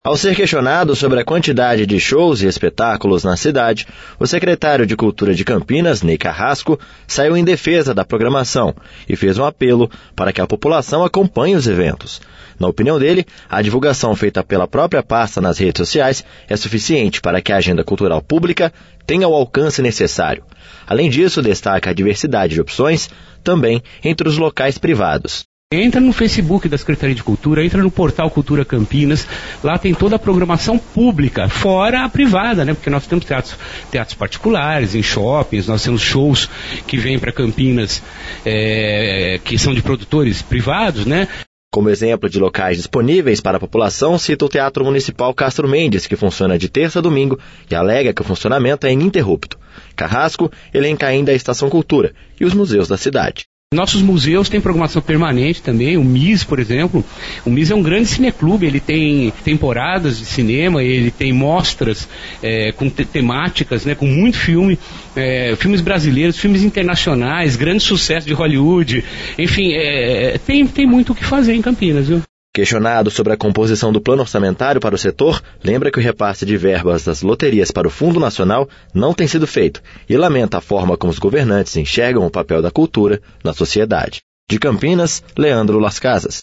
Ao ser questionado sobre a quantidade de shows e espetáculos na cidade, o secretário de Cultura de Campinas, Ney Carrasco, saiu em defesa da programação e fez um apelo para que a população acompanhe os eventos.